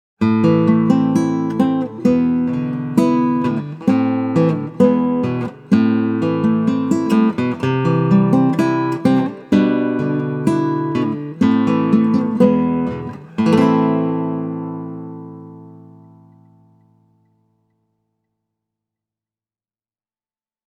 Kokopuisella kannella varustettu Jucar on kyllä todella hieno kitara, joka tarjoaa roimasti vastinetta rahallesi, mutta kokonaan kokopuusta veistetty Segura on soundiltaan ja dynamiikaltaan selvästi vielä pari askelta Jucaria edellä.
Esteve Segura -mallista löytyy iso ja dynaaminen soundi, hyvällä kokopuiselle klassiselle ominaisella laajennetulla bassotoistolla.